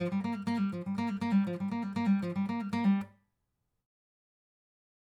Acoustic guitar
Microphones Used: AKG 411
AKG 451
Master Tempo Track : 120 BPM
acoustic_ptrn_49.wav